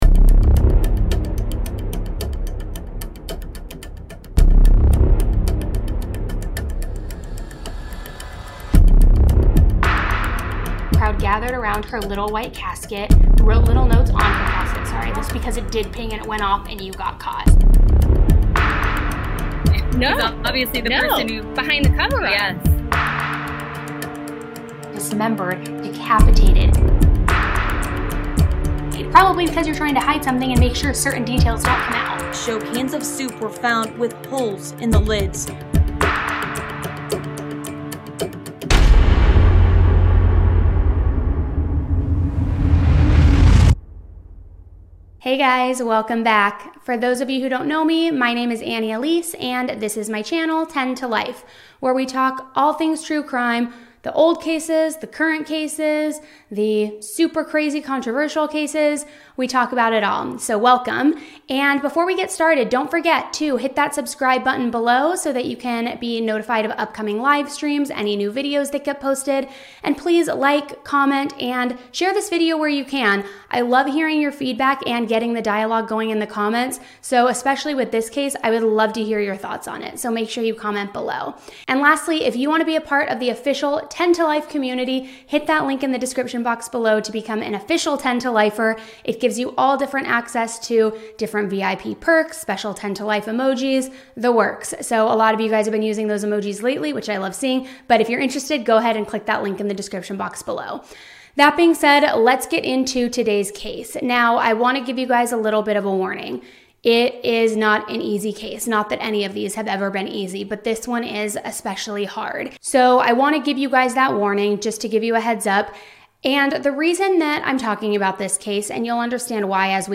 Even better, you’ll hear the story the way you’d want to: like your best friend is filling you in.